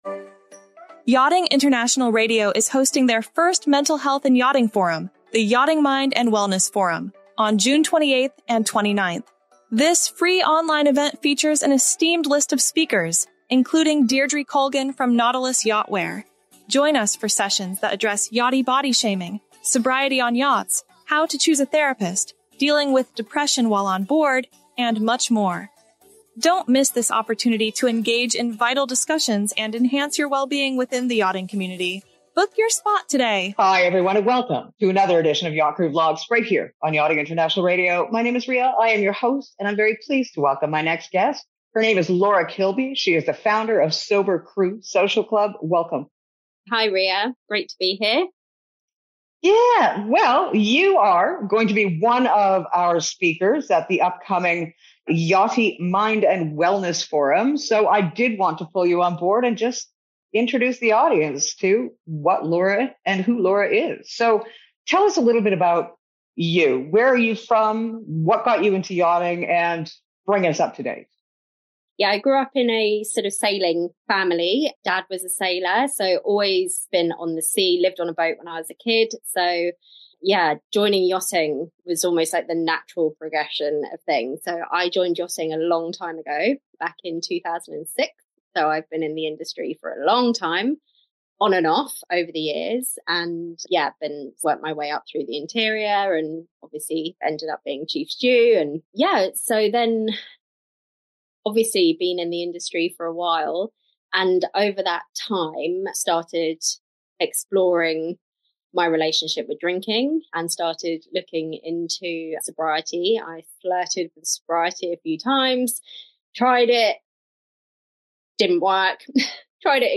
The conversation covers the link between mental health and alcohol use, the complexities of seeking support while on board, and the importance of community for those looking to change their relationship with alcohol.